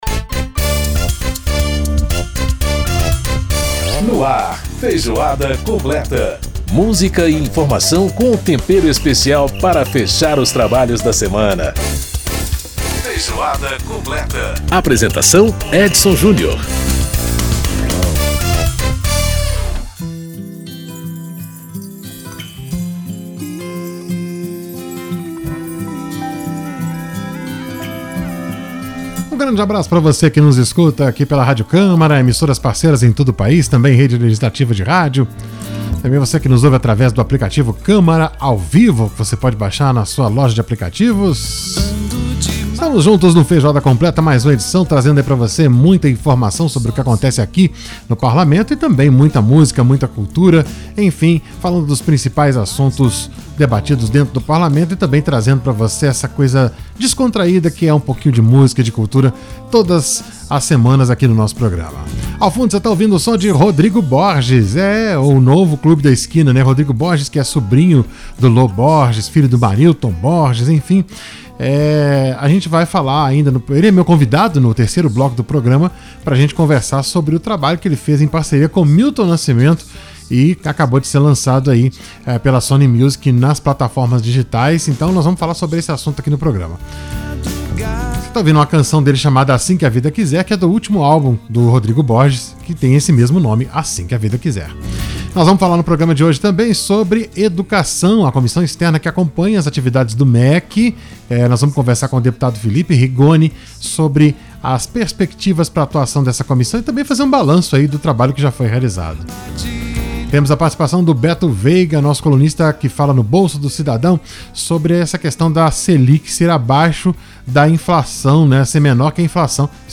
Para fazer um panorama das atividades da comissão e avaliar as perspectivas para a educação no país em 2021, Feijoada Completa recebe o deputado Felipe Rigon (PSB-ES). O parlamentar faz parte da comissão e foi o relator da regulamentação do Fundeb.